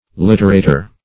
Literator \Lit"er*a`tor\ (l[i^]t"[~e]r*[=a]`t[~e]r), n. [L.